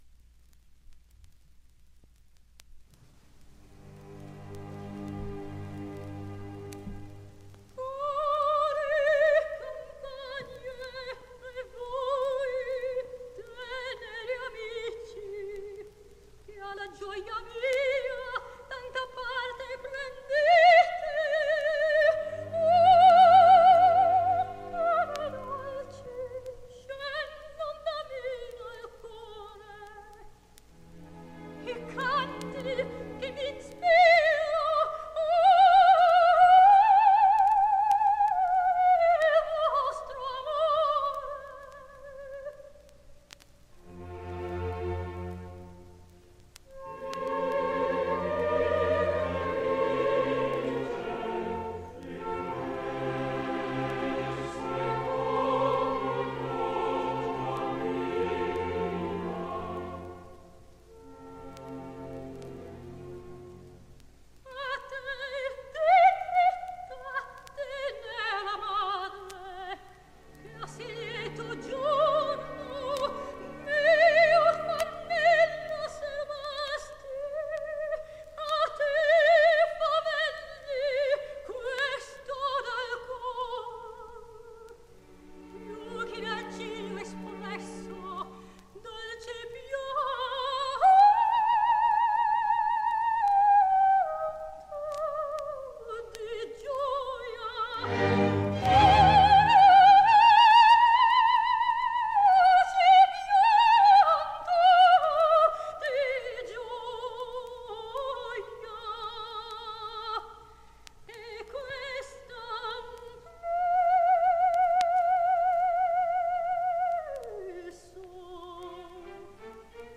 Силлс Бе́верли (Beverly Sills; Беверли Силс, настоящие имя и фамилия Белла Мириам Силверман, Belle Miriam Silverman) (25.5.1929, Нью-Йорк – 2.7.2007, там же), американская певица (сопрано).
Опера «Сомнамбула». Речитатив и каватина Амины. Симфонический оркестр Северогерманского радио.
Исполняет Б. Силлс.
В высоком регистре тембр её голоса сохранял мягкость, а  техника оставалась безупречной.